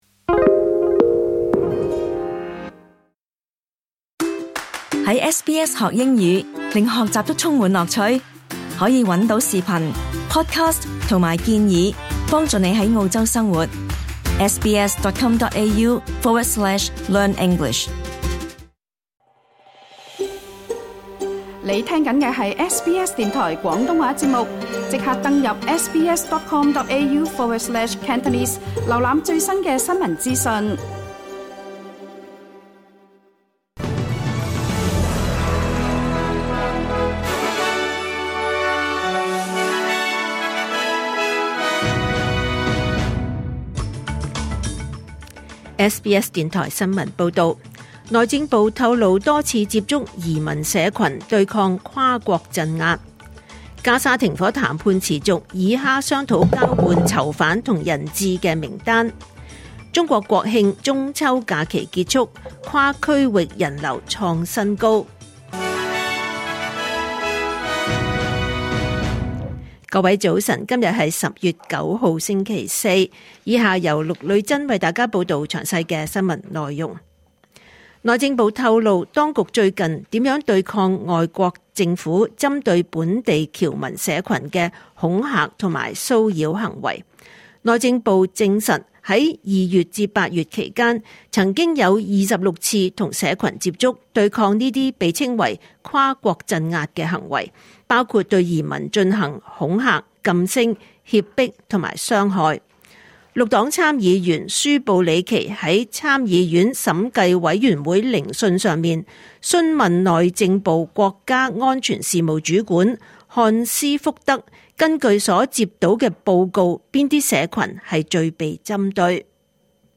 2025年10月9日SBS廣東話節目九點半新聞報道。